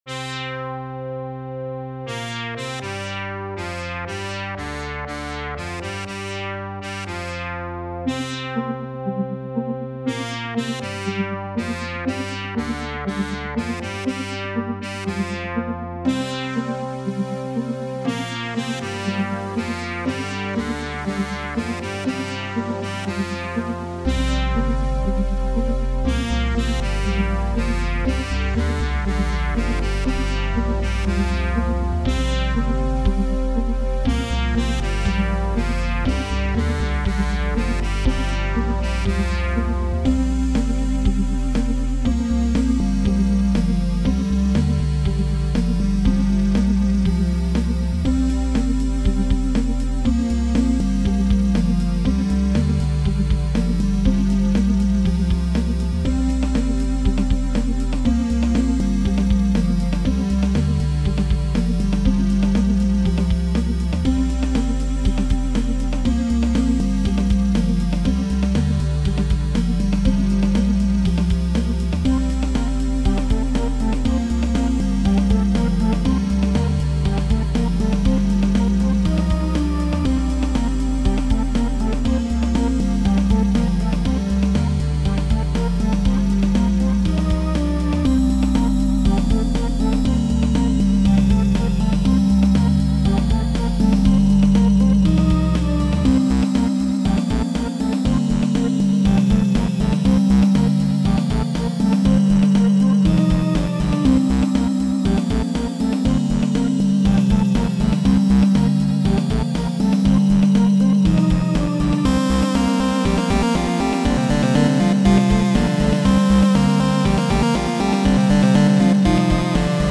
Also, the theme builds itself up a lot more.
Music / Game Music